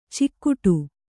♪ cikkuṭu